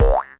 trampoline.mp3